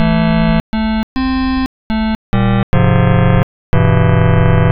first_harmonics.wav